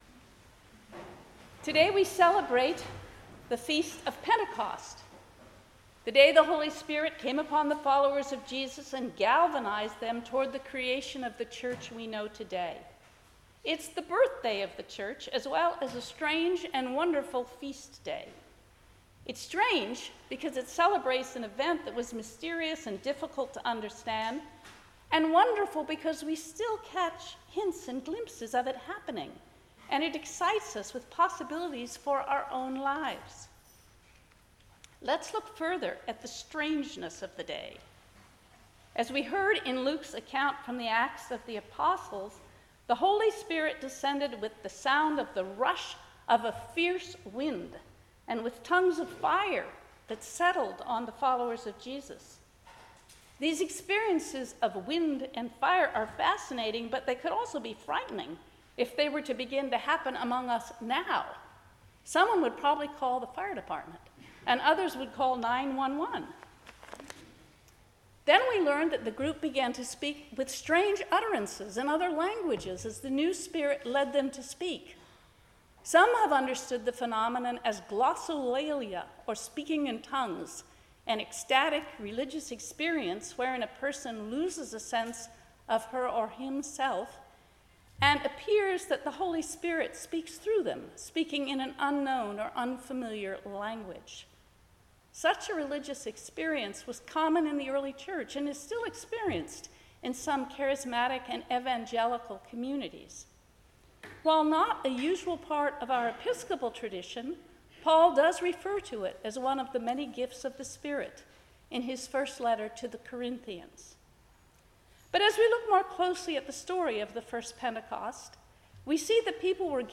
10:00 am Service